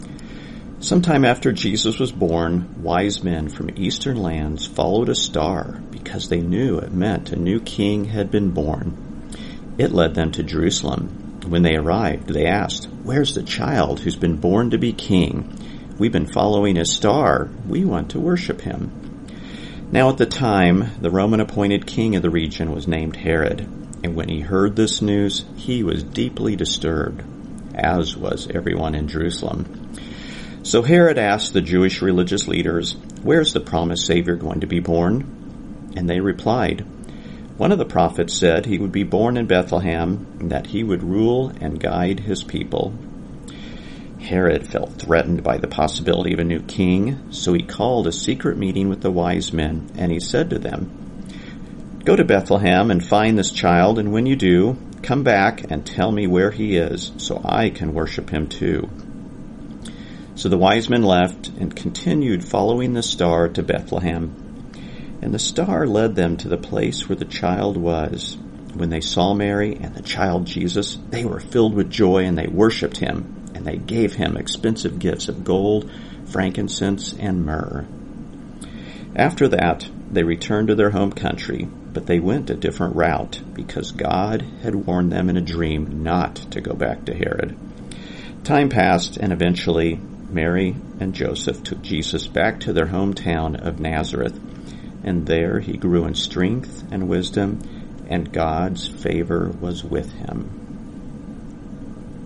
This Advent season, anticipate the celebration of Christmas with oral Bible stories.